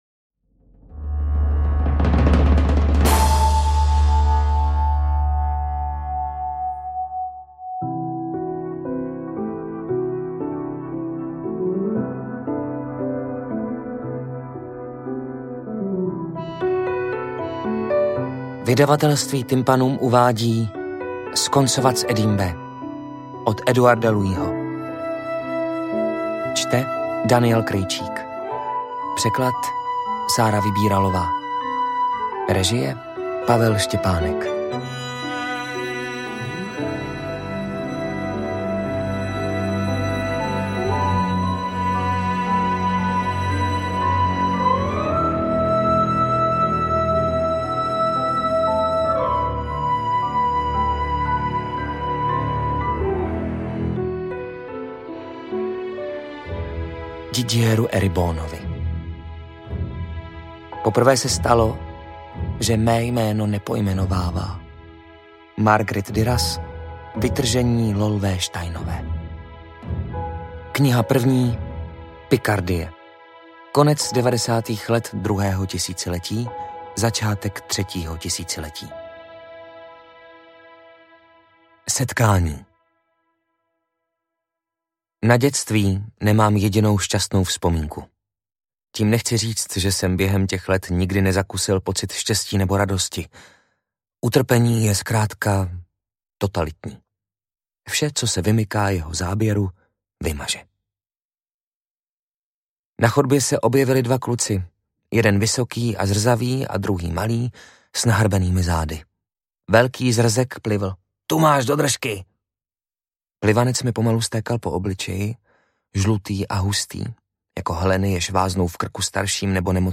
AudioKniha ke stažení, 25 x mp3, délka 4 hod. 36 min., velikost 253,6 MB, česky